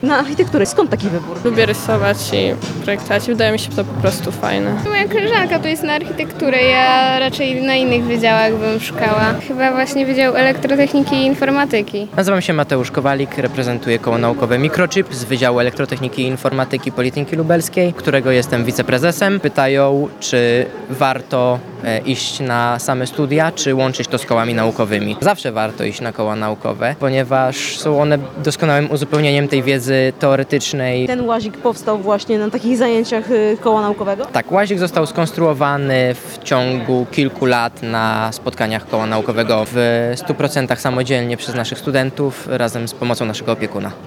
Na miejscu była reporterka Radia Lublin
Interesuje mnie Wydział Elektrotechniki i Informatyki – mówią zwiedzające.